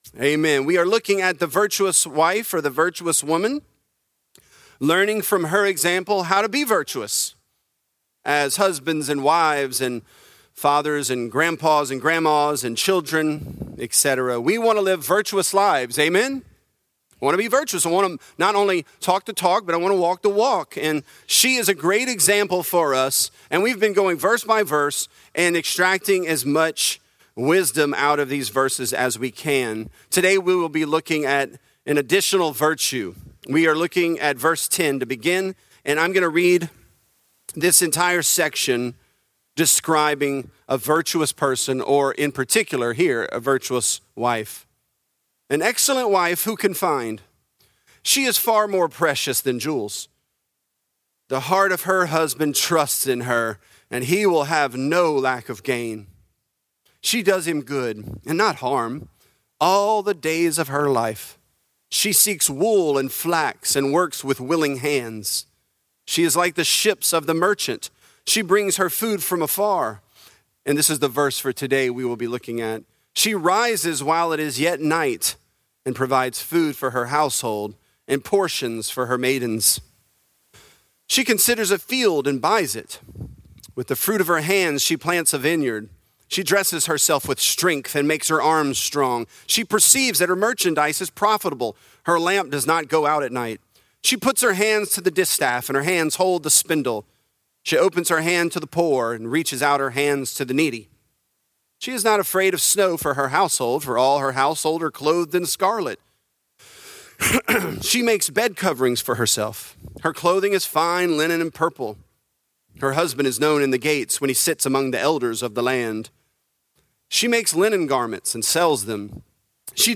Virtuous: Portions For Her Maidens | Lafayette - Sermon (Proverbs 31)